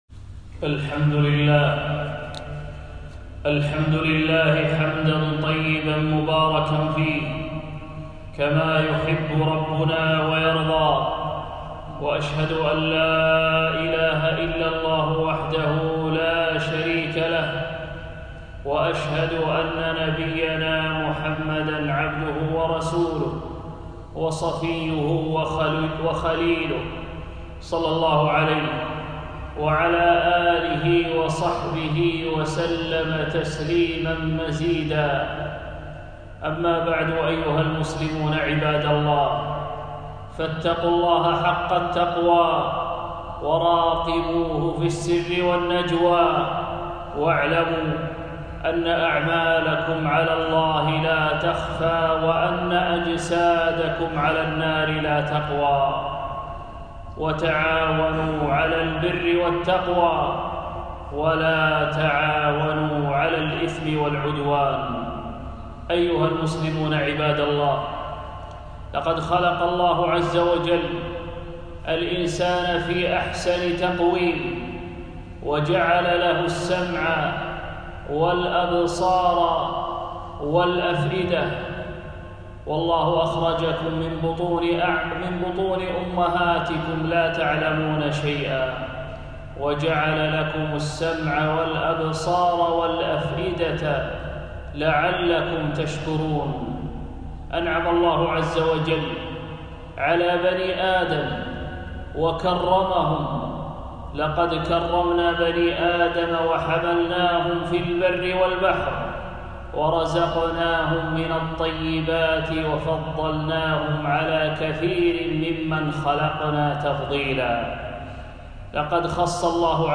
خطبة - اللسان بين الطاعة و المعصية